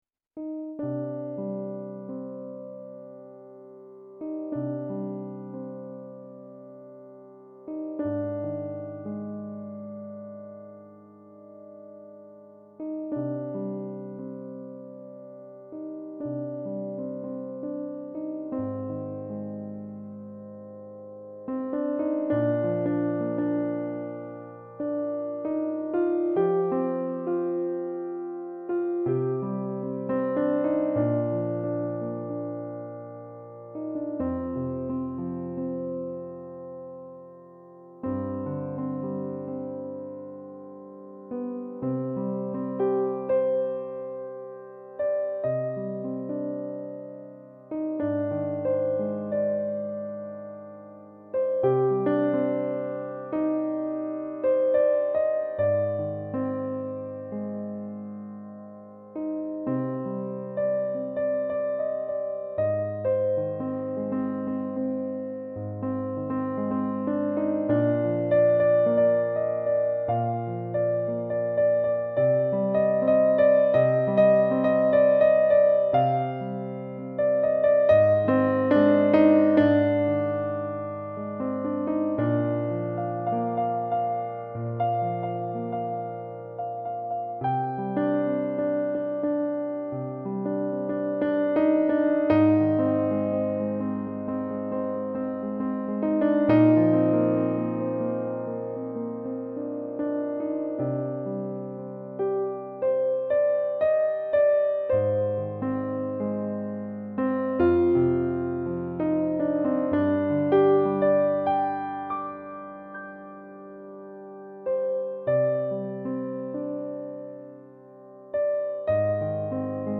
Klavierstücke